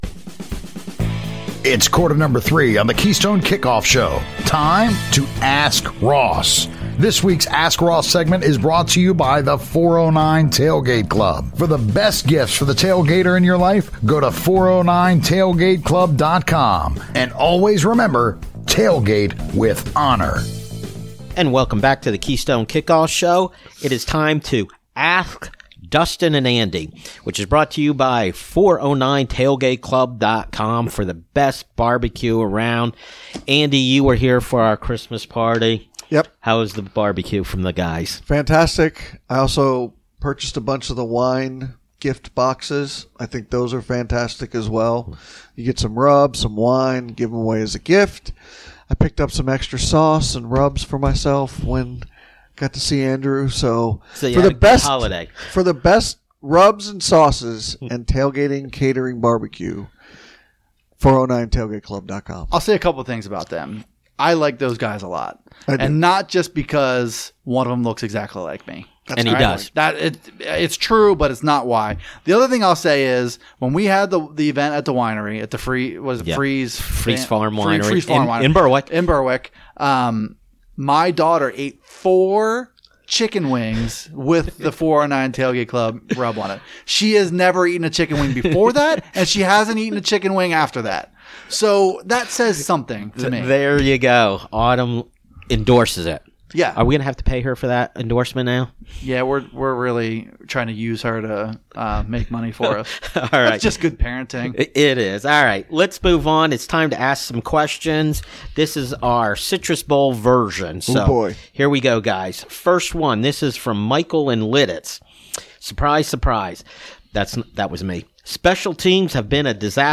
The guys answer all your questions from the Citrus Bowl!